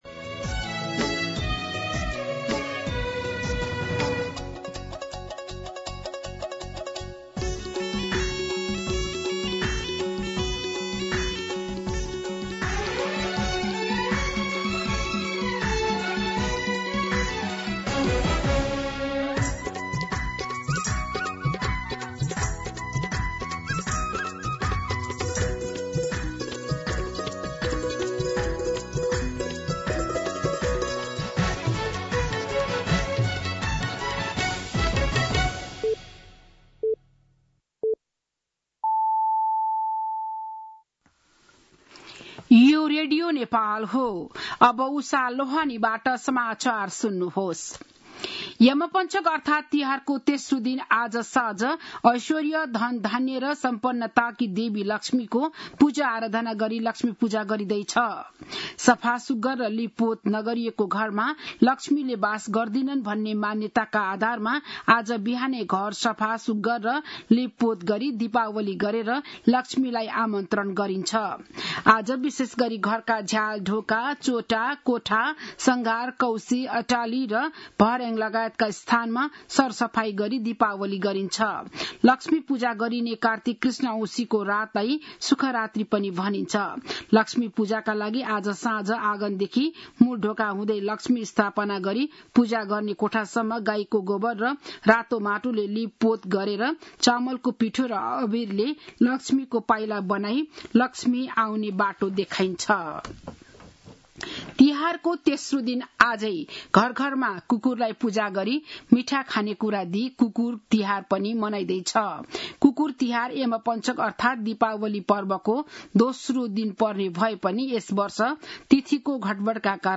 बिहान ११ बजेको नेपाली समाचार : ३ कार्तिक , २०८२
11am-Nepali-News-1.mp3